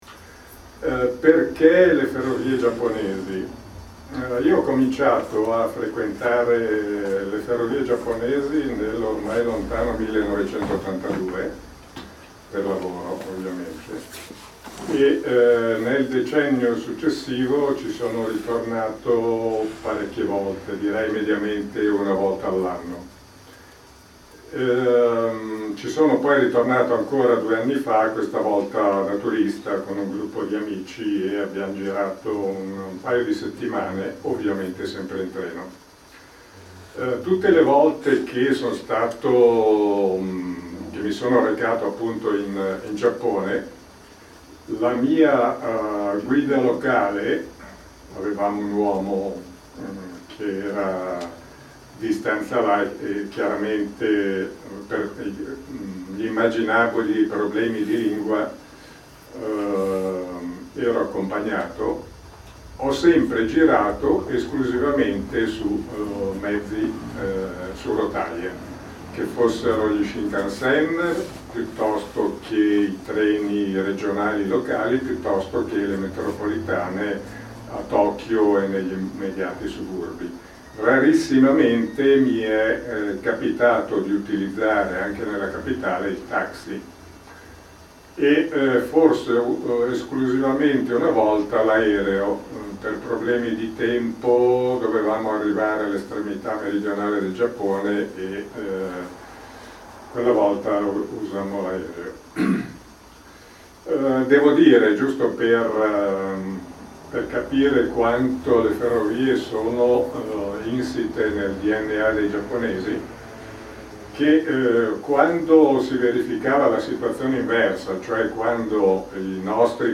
È disponibile la registrazione audio della conferenza del 10 aprile 2015: